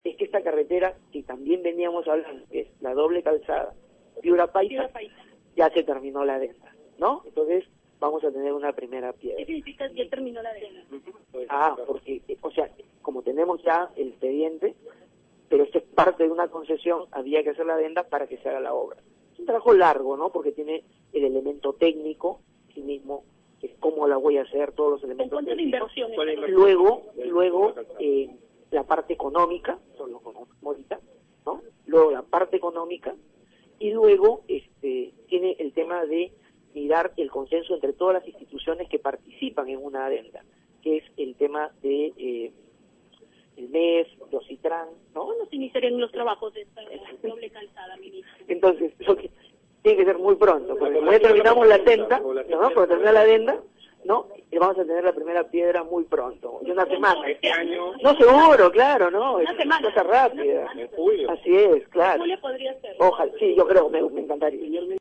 Las declaraciones las brindó al término de la reunión que sostuvo con el gobernador regional, Reynaldo Hilbck, y el alcalde de Sullana, Carlos Távara Polo.